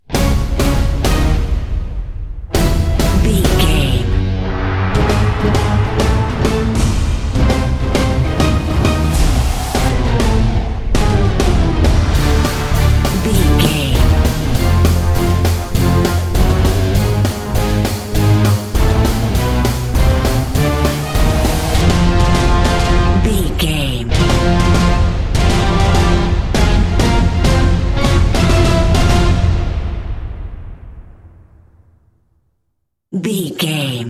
Epic / Action
Aeolian/Minor
brass
violin
cello
percussion